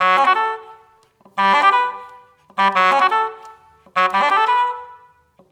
Key-Basoon_19.1.1.wav